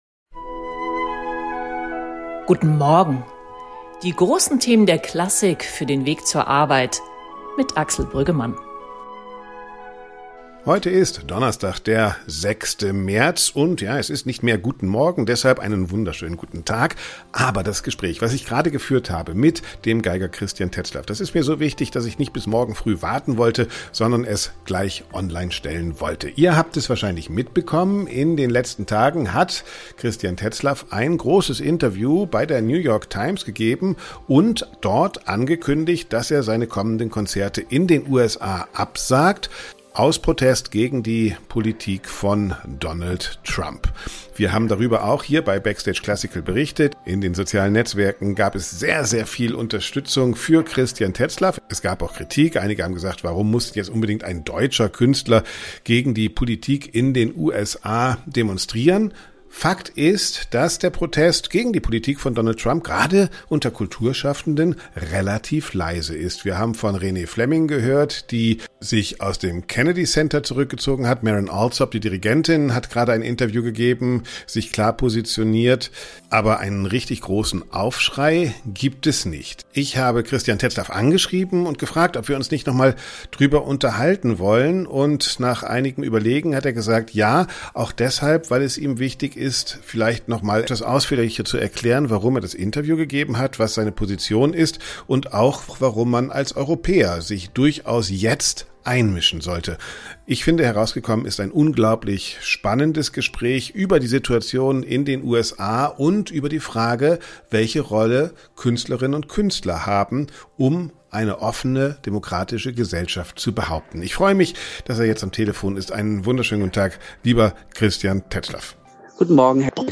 In einem ausführlichen Gespräch im BackstageClassical-Podcast erläuterte Tetzlaff seine Beweggründe und zeigte sich tief besorgt über die aktuelle politische Entwicklung in den Vereinigten Staaten.